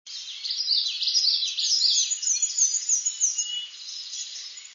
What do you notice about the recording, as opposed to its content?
Indian Head Point, Mohican Outdoor Center, Delaware Water Gap, 6/24/02, 5:30 a.m. with black and white warbler and trailing Towhee (19kb)